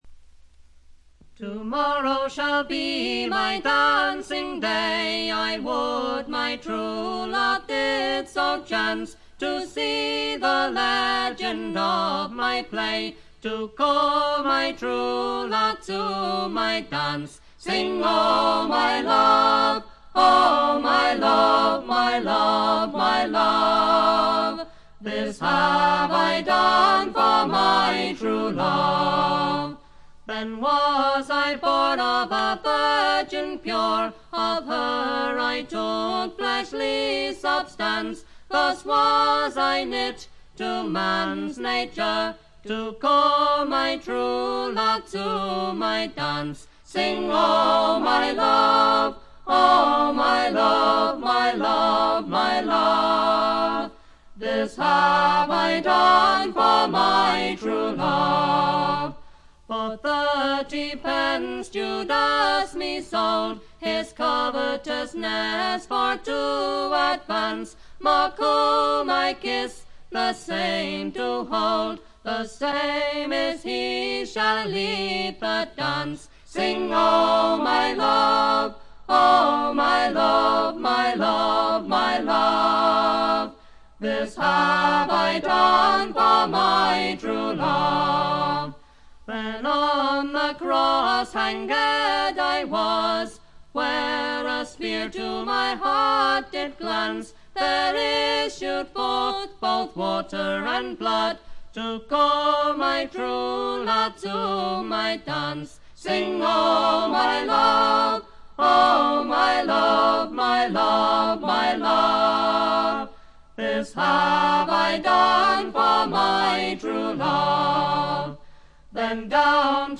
特にオルガンのひなびた音色とかたまらんものがあります。
あまりトラッド臭さがなくほとんどドリーミーフォークを聴いているような感覚にさせてくれる美しい作品です。
試聴曲は現品からの取り込み音源です。
Fiddle, Recorder, Vocals